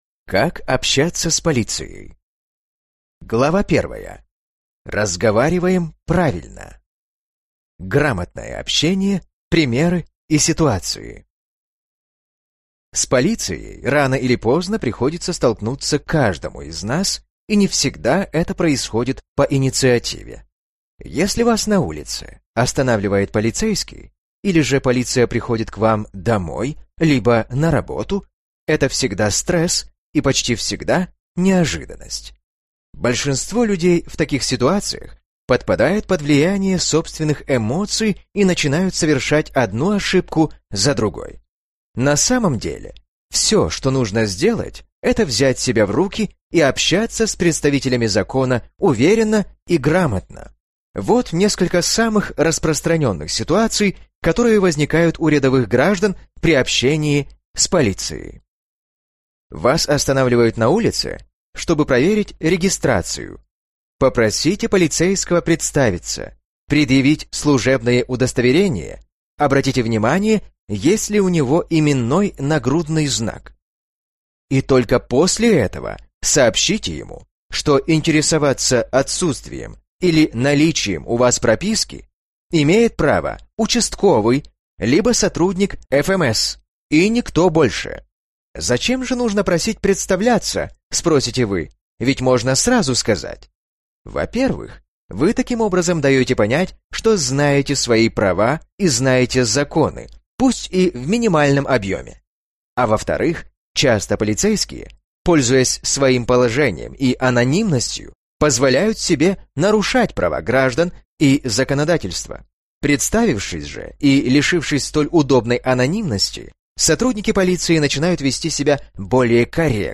Аудиокнига Как общаться с Полицией | Библиотека аудиокниг